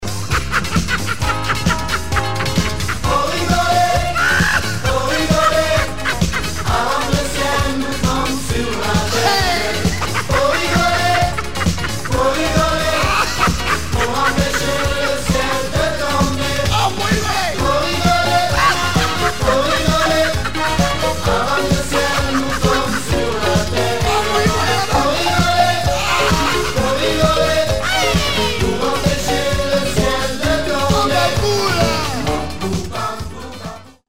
Seul le refrain est chanté.